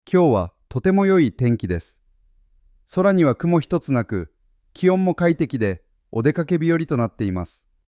AIが自然な間や抑揚をつけるためには、句読点が重要です。